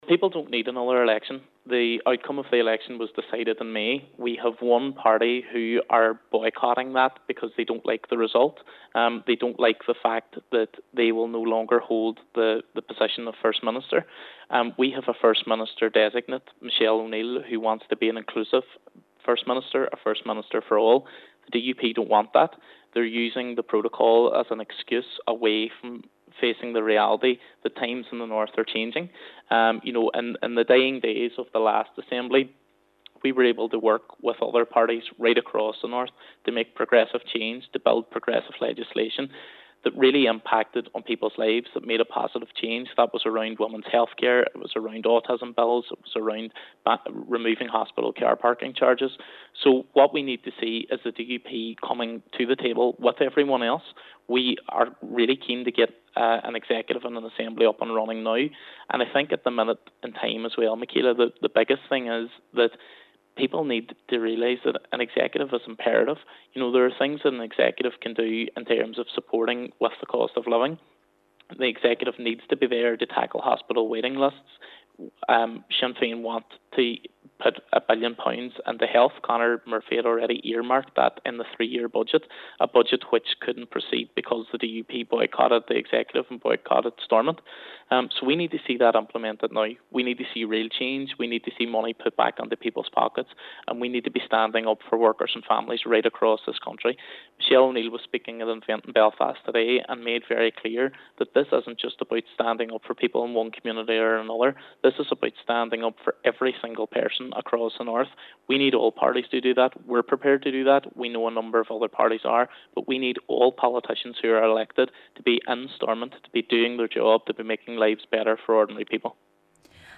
He says a functioning Executive is what is needed: